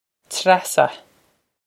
Treasa Trass-uh
This is an approximate phonetic pronunciation of the phrase.